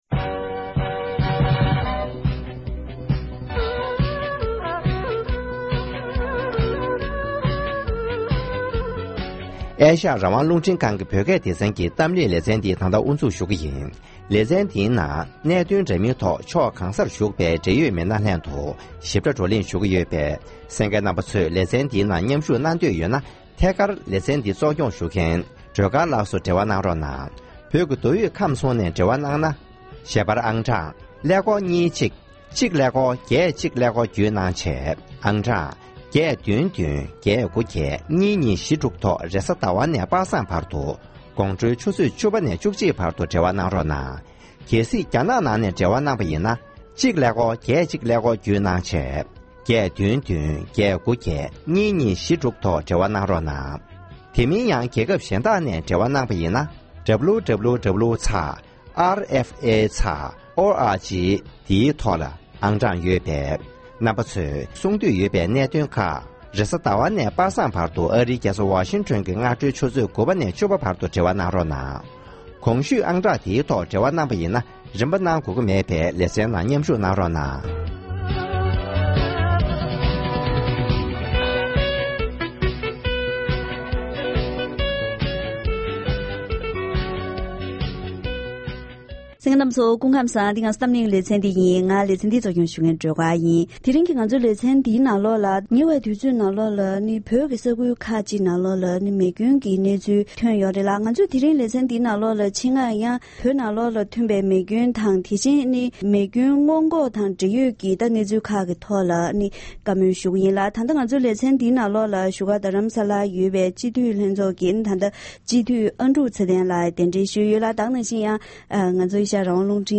༄༅༎དེ་རིང་གི་གཏམ་གླེང་ལེ་ཚན་ནང་ཉེ་ཆར་བོད་ཁུལ་གྱི་ས་གནས་ཁག་ཅིག་ནང་མེ་སྐྱོན་གྱི་གནོད་འཚེ་ཐོན་པར་བརྟེན་ནས་བོད་ནང་གི་དགོན་པ་ཚུད་པའི་མི་དམངས་འདུ་འཛོམས་མང་སར་མེ་སྐྱོན་གྱི་སྔོན་འགོག་བྱ་ཐབས་དང་མི་སྒེར་སོ་སོའི་ངོས་ནས་མེ་སྐྱོན་གྱི་གནོད་འཚེ་དོ་སྣང་བྱ་ཕྱོགས།